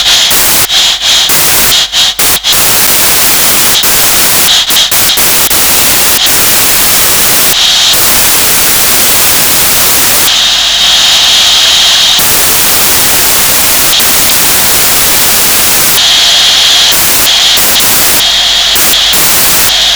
Advance the track control slowly and the steam volume rises and starts to make a deep chugging sound mimicking the cylinders labouring. As you increase speed so a more mellow chuff is heard.
running.wav